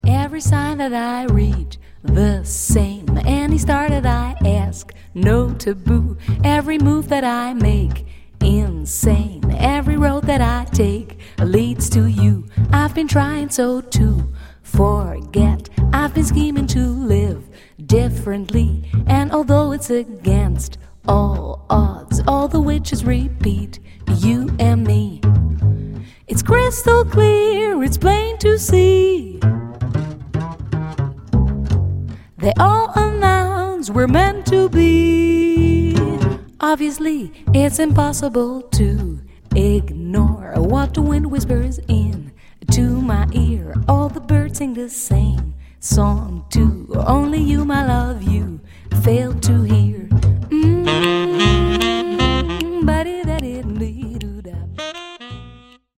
bouncy composition